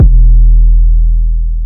TM 808 BOOMING KICK.wav